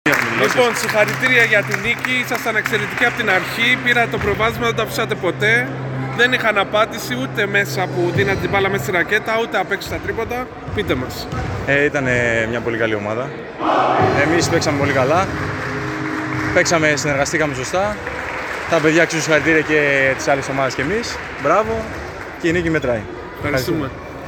GAME INTERVIEWS:
Παίκτης REAL CONSULTING